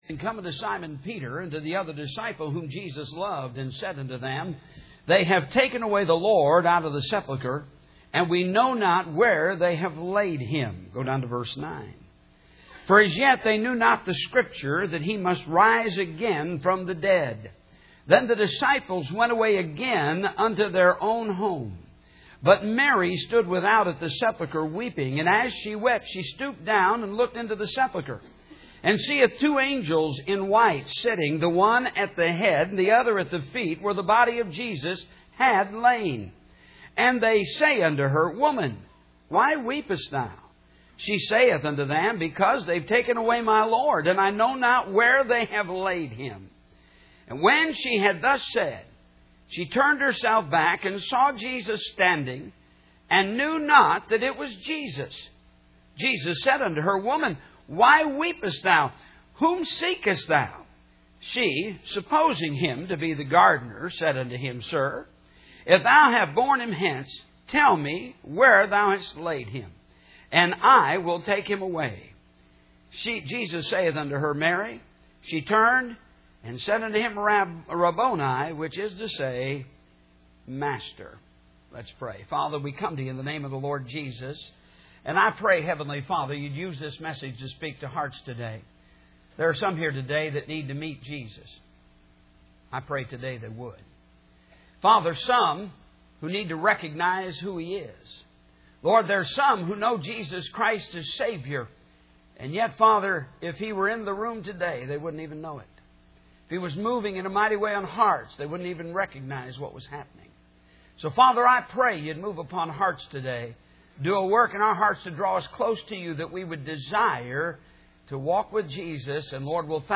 Welcome Pastoral Staff Schedule/Directions Church Calendar Ladies Array Christian Education 25th Anniversary Missionaries Media Photos Audio Video Sermon Audio Ministries Contact 840 Balch Rd.